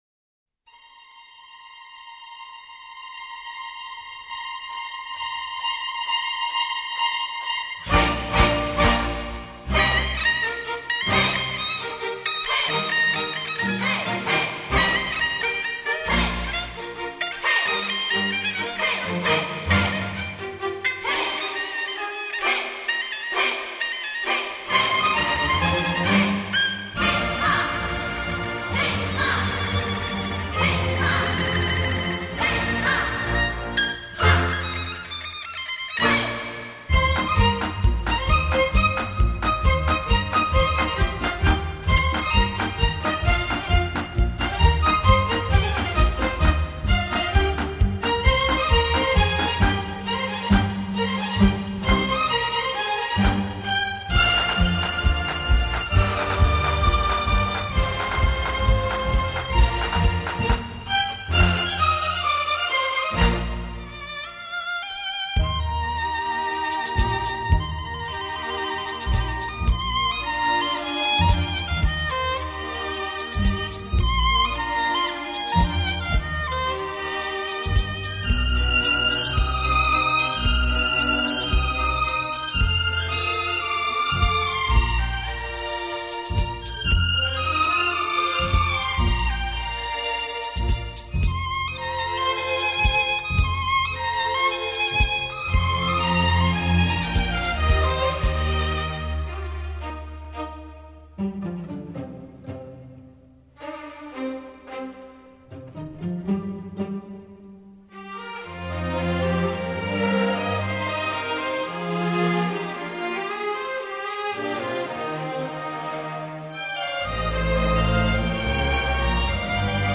鏗鏘有力、乾淨俐落的節奏搭配銅管及打擊樂器，
使音樂充滿理性及豪邁氣概，